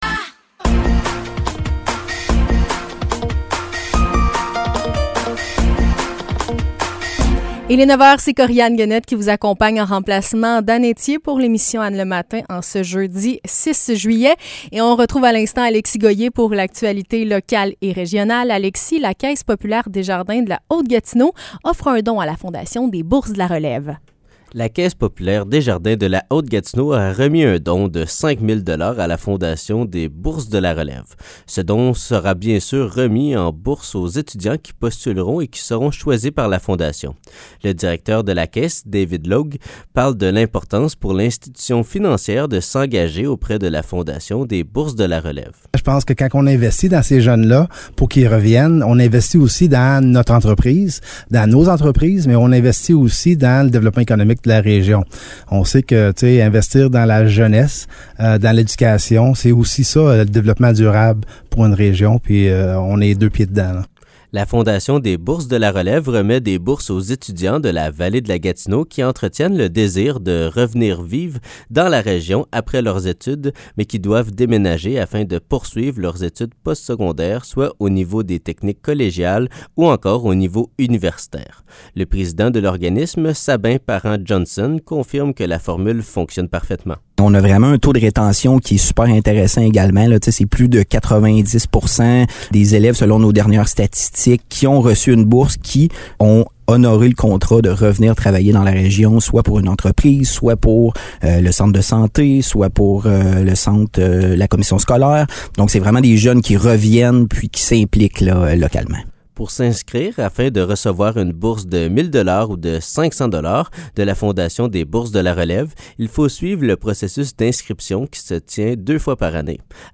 Nouvelles locales - 6 juillet 2023 - 9 h